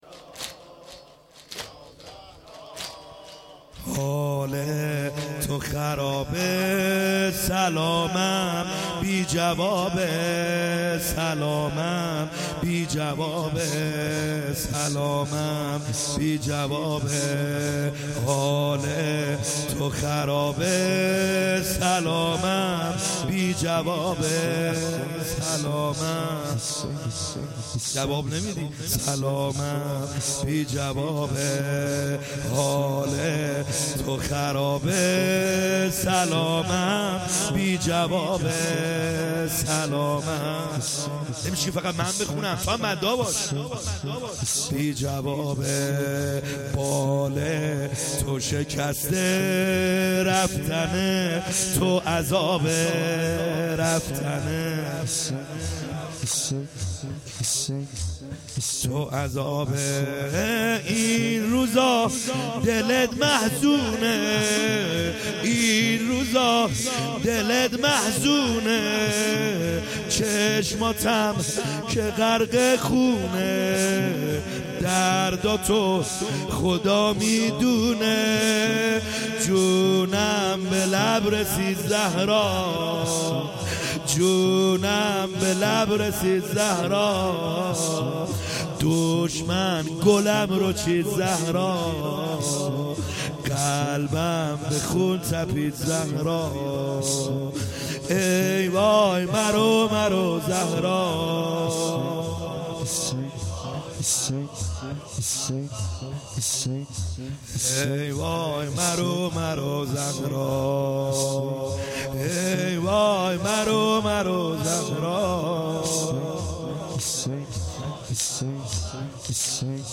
خیمه گاه - بیرق معظم محبین حضرت صاحب الزمان(عج) - زمینه | حال تو خرابه